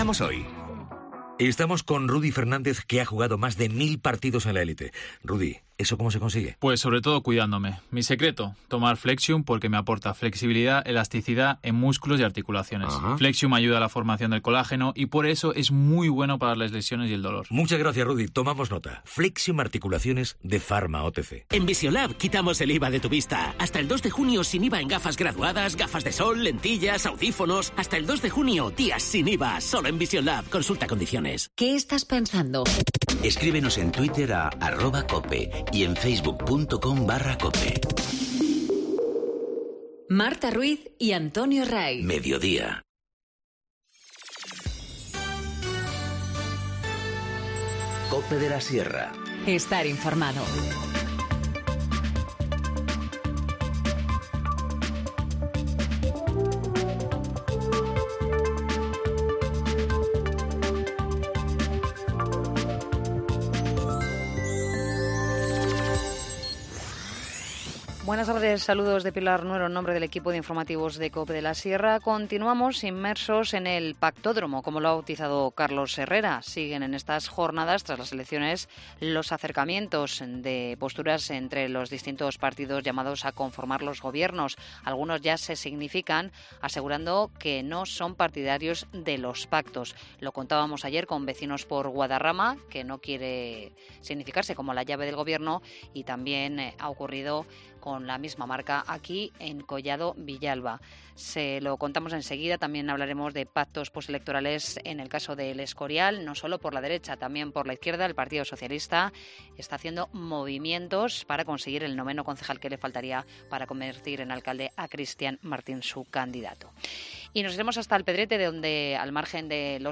Informativo Mediodía 30 mayo 14:20h